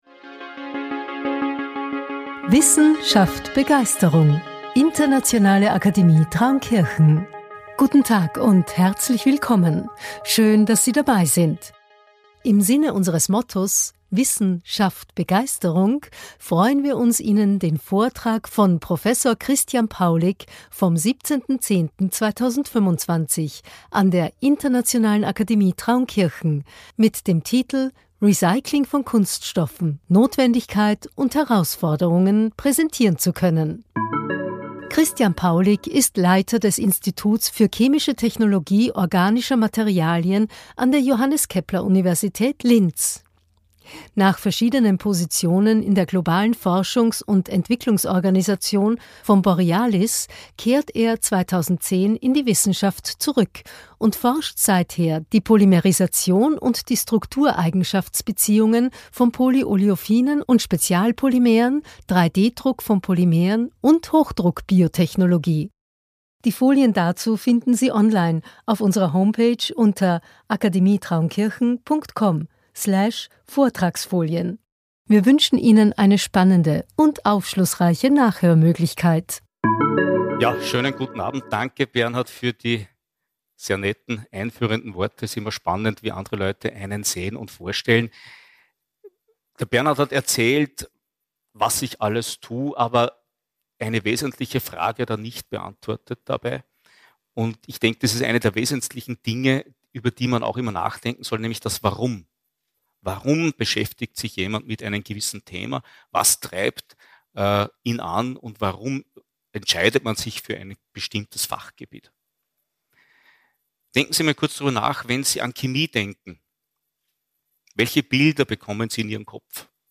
IAT Science-Cast Folge 06 Recycling von Kunststoffen – Notwendigkeit und Herausforderung ~ IAT ScienceCast - Einblicke in Naturwissenschaft und Technik aus dem Klostersaal Traunkirchen Podcast